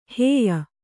♪ hēya